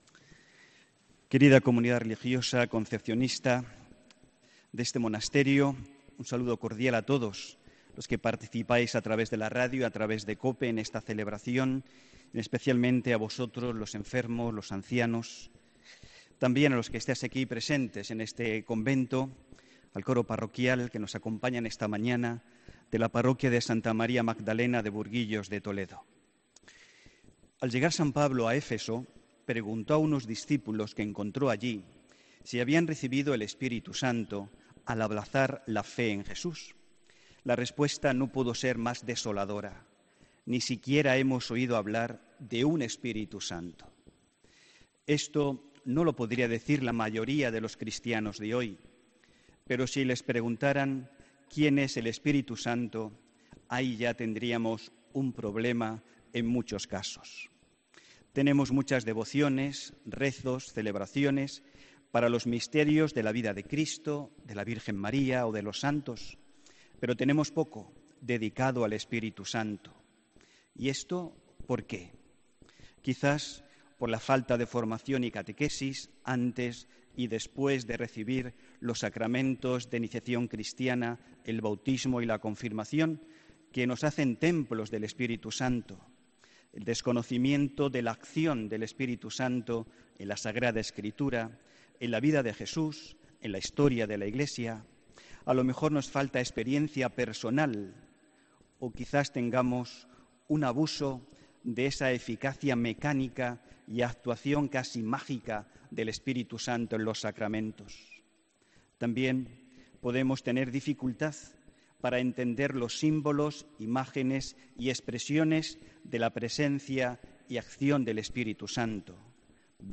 HOMILÍA 20 MAYO 2018